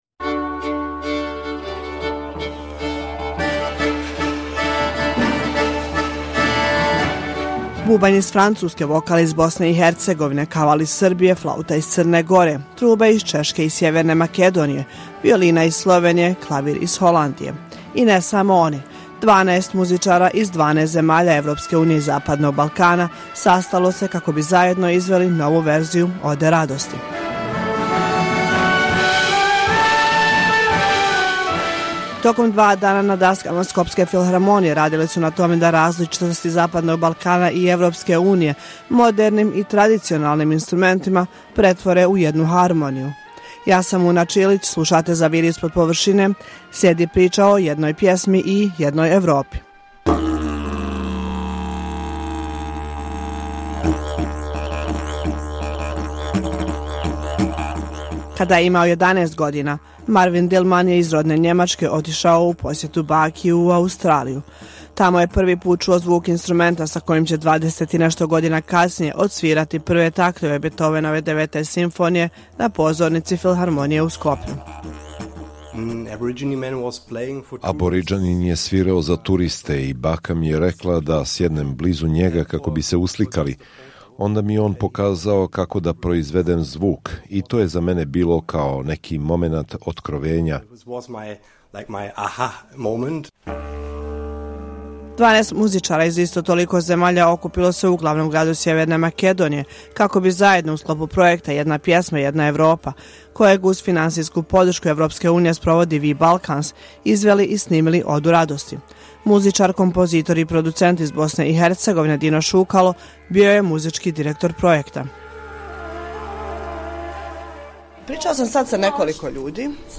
Bubanj iz Francuske, vokal iz Bosne i Hercegovine, kaval iz Srbije, flauta iz Crne Gore, trube iz Češke i Sjeverne Makedonije, violina iz Slovenije, klavir iz Holandije.